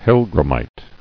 [hell·gram·mite]